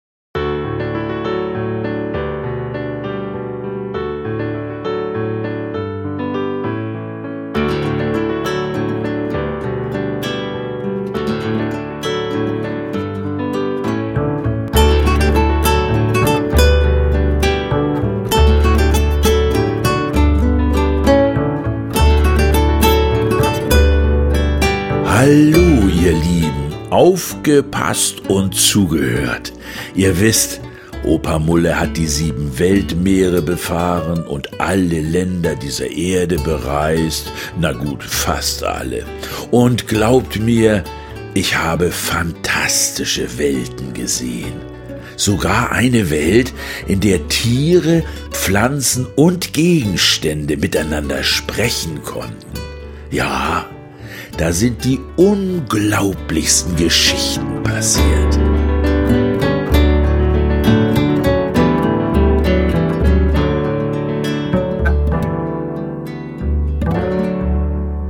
Hörbuch-CD oder als Download, mit Liedern zum Mitsingen und Tanzen, 6,90 €
Intro – Hörbuch